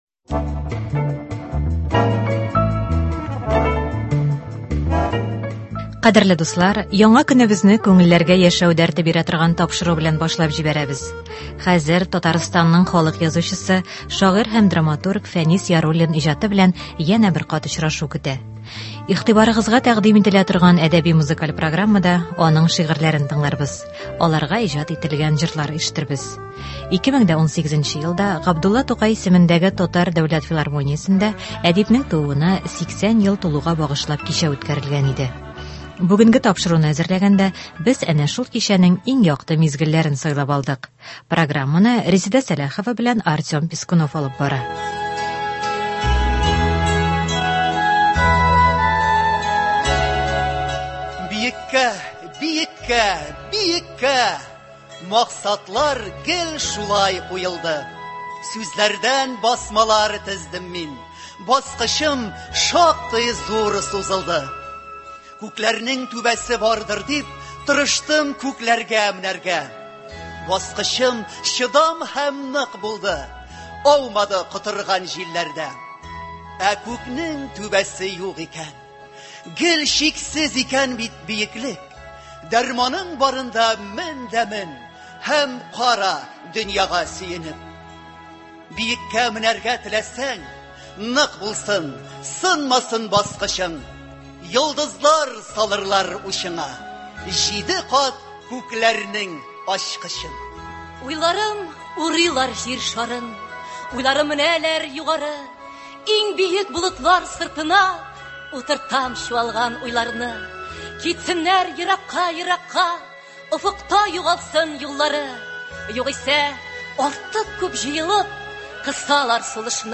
Фәнис Яруллин әсәрләреннән әдәби- музыкаль композиция.
Хәзер Татарстанның халык язучысы, шагыйрь һәм драматург Фәнис Яруллин иҗаты белән янә бер кат очрашу көтә. Игътибарыгызга тәкъдим ителә торган әдәби-музыкаль программада аның шигырьләрен тыңларбыз, аларга иҗат ителгән җырлар ишетербез.